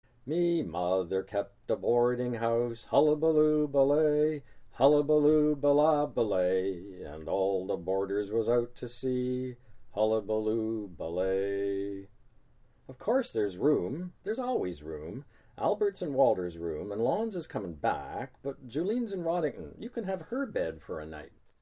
John Steffler reads [Me mother kept a boarding house] from The Grey Islands